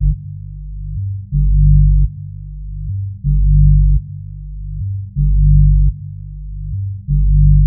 Sub bass : когда надо и когда не надо?